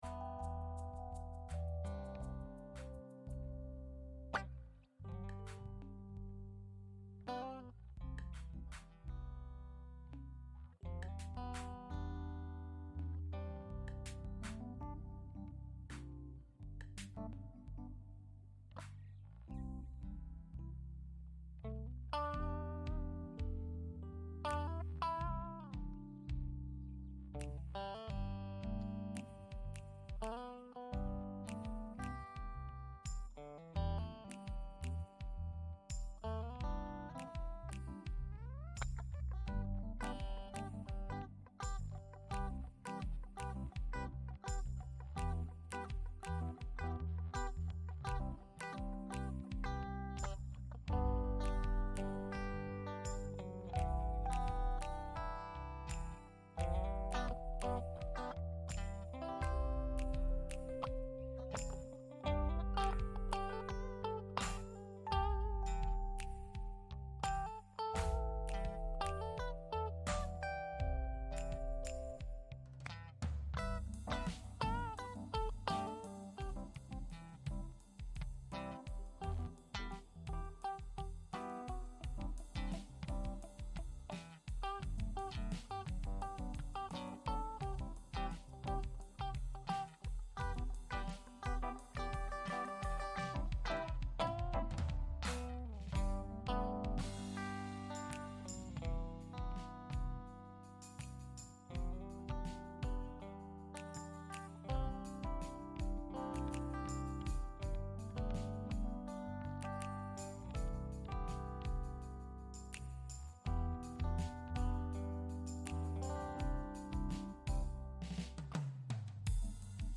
Gottesdienst am 2. Juni 2024 aus der Christuskirche Altona